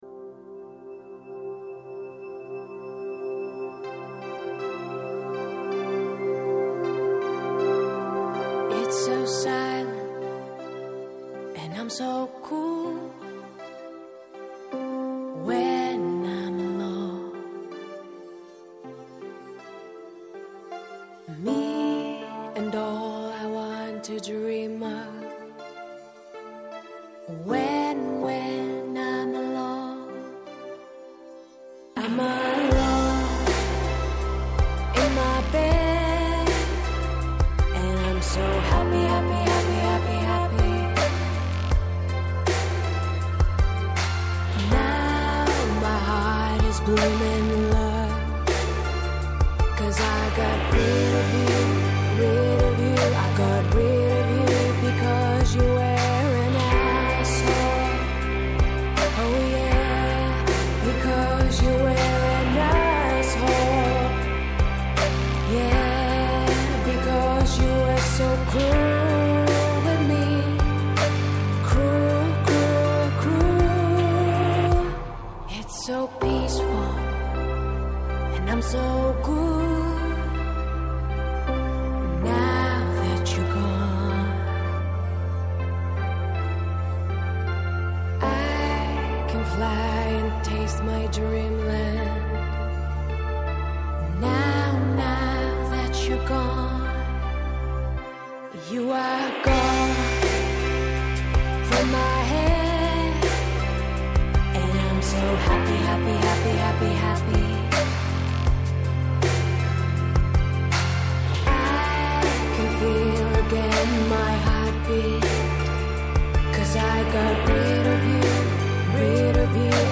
σε μια συζήτηση για το κοινό μέσο έκφρασης όλων, τη γραφή.